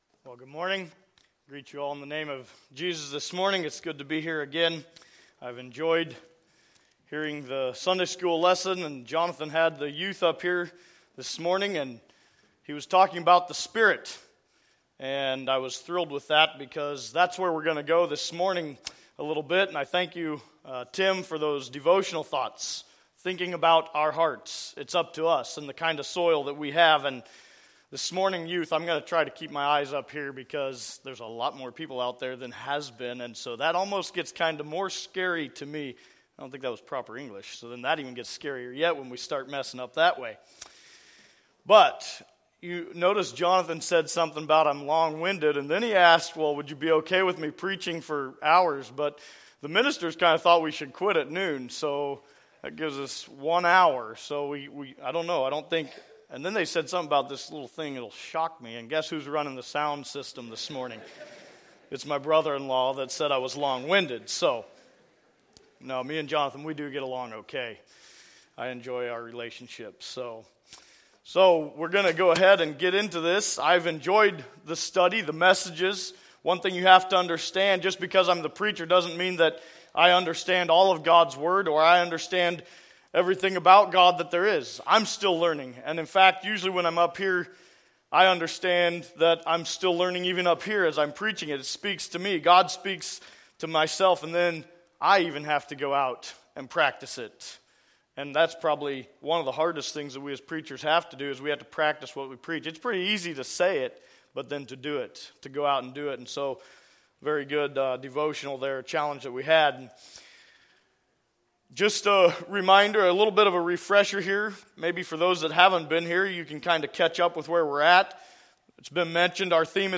Youth Rally 2025 Like this sermon?